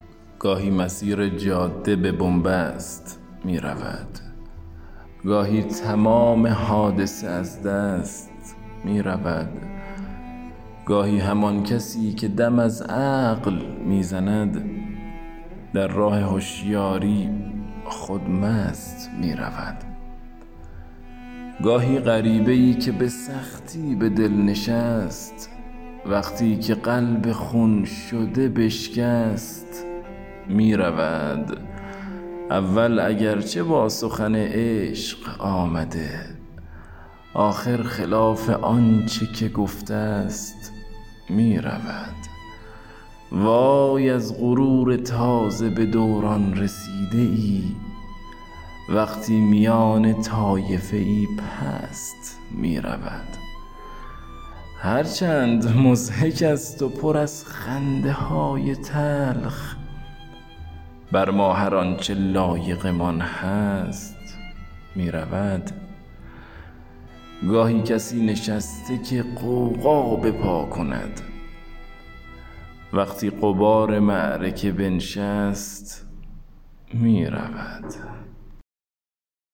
دانلود دکلمه گاهی مسیر جاده با صدای افشین یداللهی
گوینده :   [افشین یداللهی]